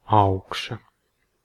Ääntäminen
IPA : /ˈtɒp/ IPA : [tʰɒp] GenAm: IPA : /ˈtɑp/ IPA : [tʰɑp]